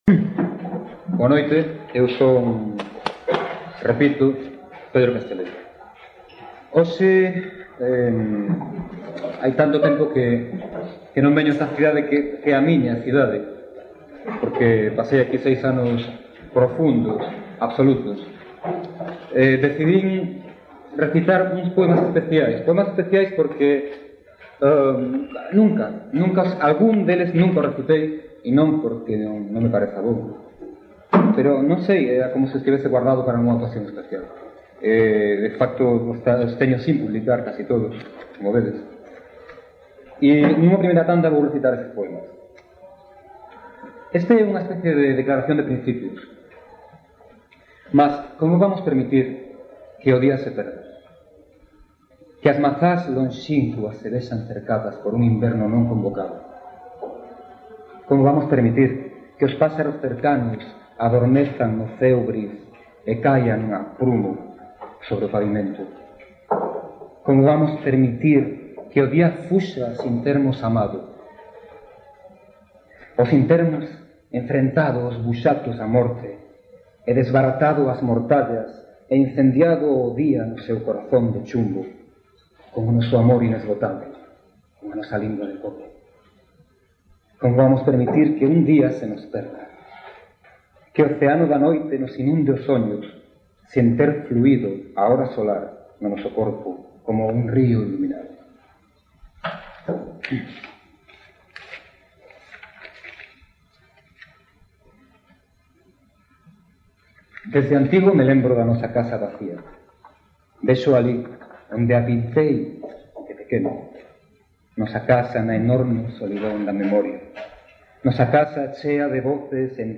Dixitalizaci�n de audio: Reverso CCL.
Gravaci�n realizada no pub Tarasca de Santiago de Compostela (r�a Entremuros, 13) o 28 de agosto de 1998. Recital organizado por Letras de Cal.